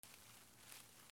木の葉をゆらす
/ M｜他分類 / L01 ｜小道具